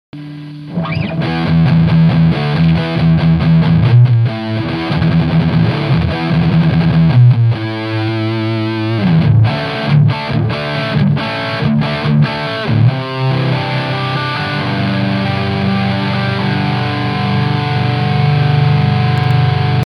Gitara UPG, snimac KA PAF. V kazdom preste som vypol reverb, nech je menej pukancov, mam slaby pocitac, nie?
Sumu je tam neurekom, aj preto hravam cca na 1/3 gaine ako si mal nastaveny ty.
Mp3 su stereo, pre tvoje pohodlie.
okrem toho ti to este pulzuje hlasitostne - tvrdo to limutuje, co znamena ze mas prevaleny este aj output